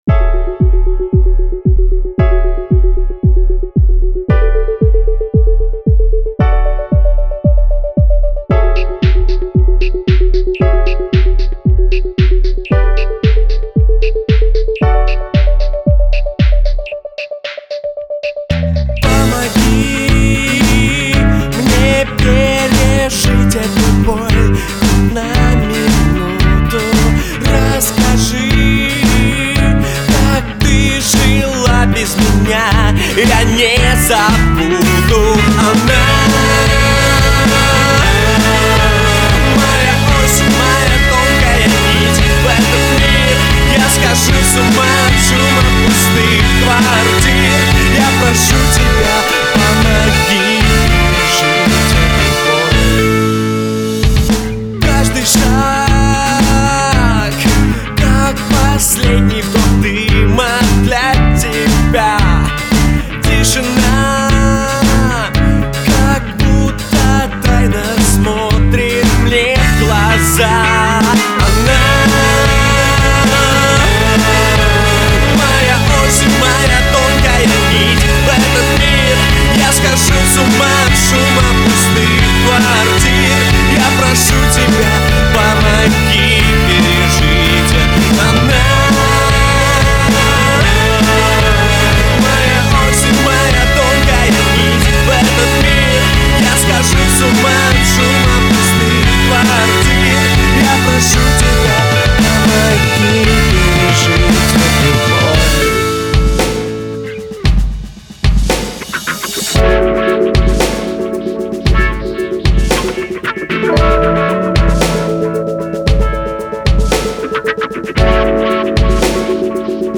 вокал, гитара
бас
ударные.
Наиболее качественная и востребованная indie-группа города.